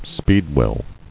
[speed·well]